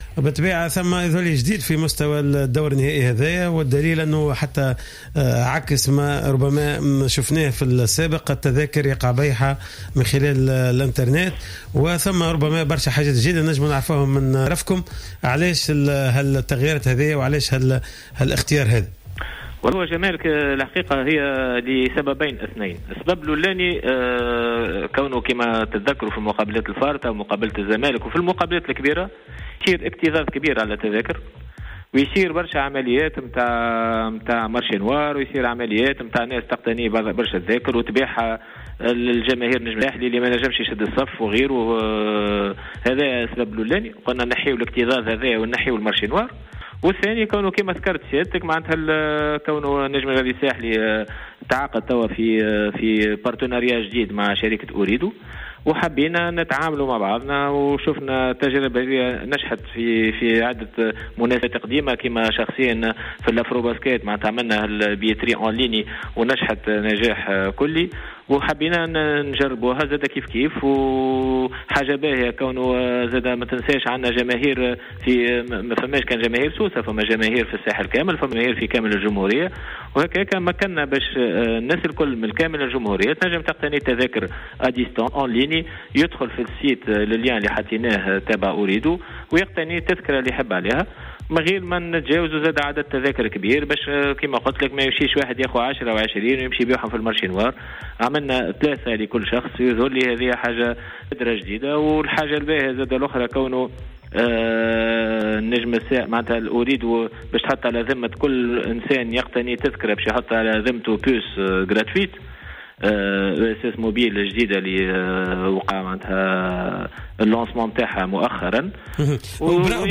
مداخلة في برنامج Cartes sur table